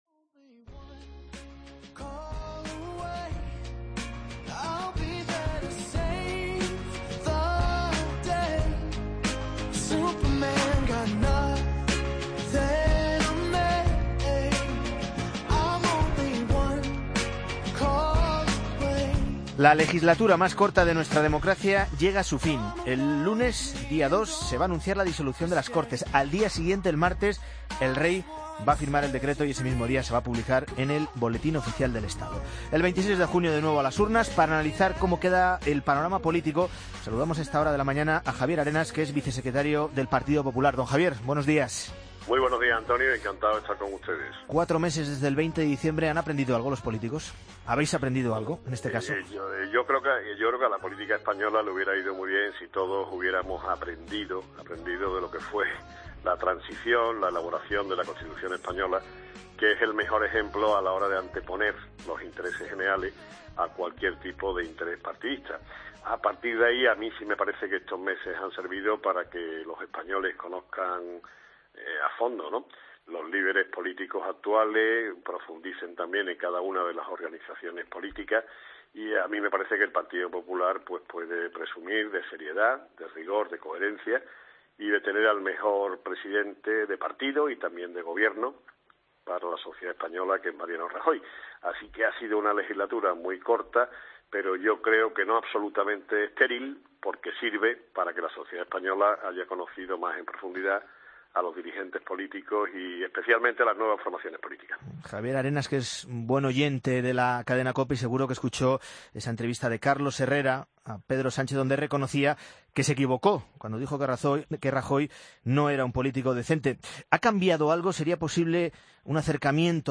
AUDIO: Entrevista a Javier Arenas, vicesecretario de Autonomías y Ayuntamientos del PP en 'La Mañana Fin de Semana'